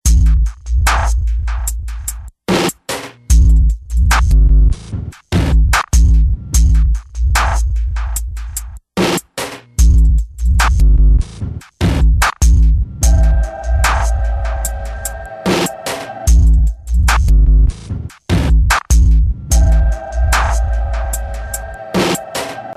une boucle